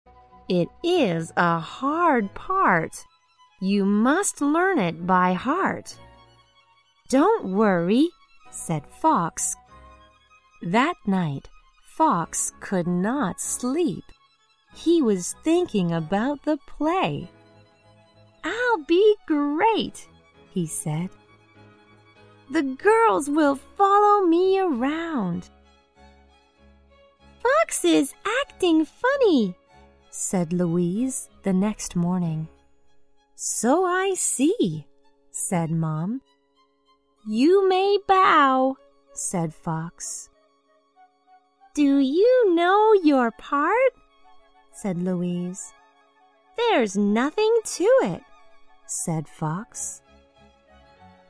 在线英语听力室小狐外传 第31期:台词的听力文件下载,《小狐外传》是双语有声读物下面的子栏目，非常适合英语学习爱好者进行细心品读。故事内容讲述了一个小男生在学校、家庭里的各种角色转换以及生活中的趣事。